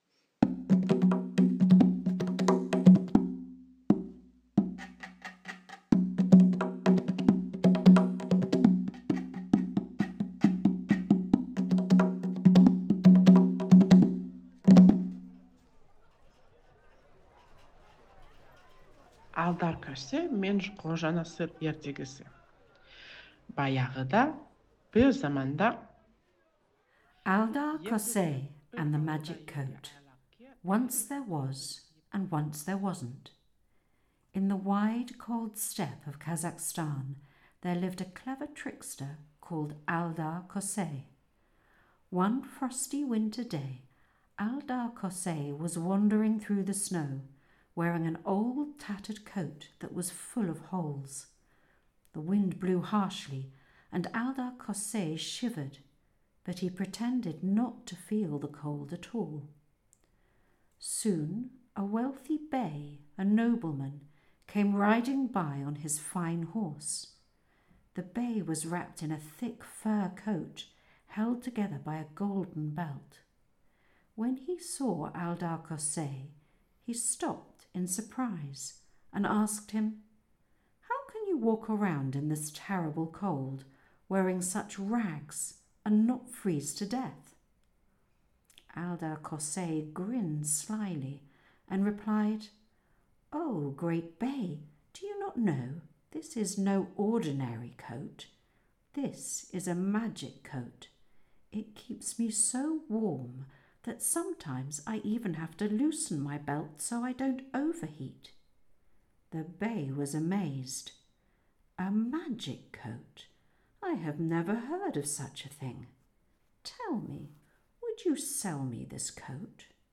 Es war einmal, es war einmal nicht ist eine Online-Ausstellung und Audio-Erfahrung, die als wachsende Sammlung von Volksmärchen konzipiert ist, die von Frauen mit Migrationshintergrund aus den Ländern entlang der alten Seidenstraße – die sich von Japan bis Italien erstreckt – erzählt und durch zeitgenössische Kunstfotografie illustriert werden.